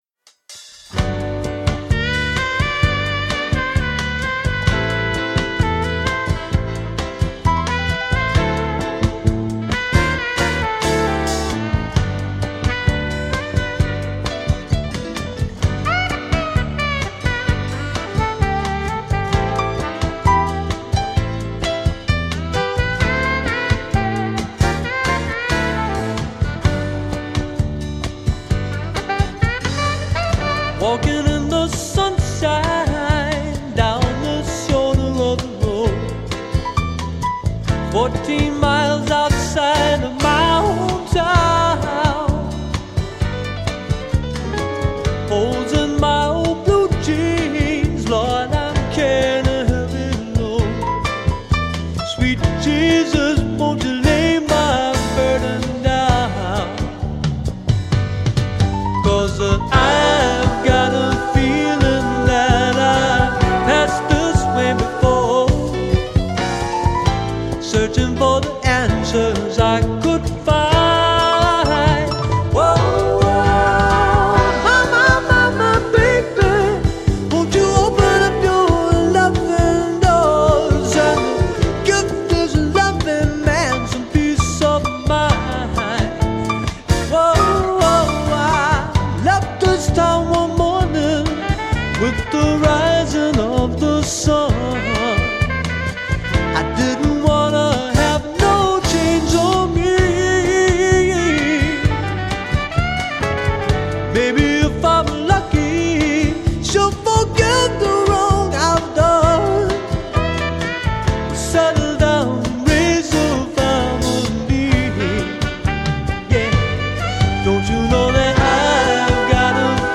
Recorded at Autumn Sound, Dallas.
Guitar